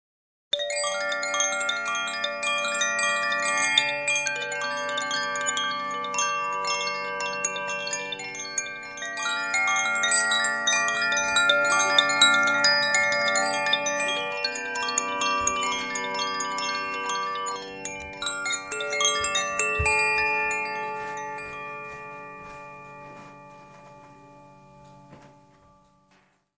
描述：来自两个koshi风铃水和火的声音，序列：aquafireaquafire aqua，用联想Moto Z Play录制 我的声音是免费的。
标签： 放松 水产 风编钟
声道立体声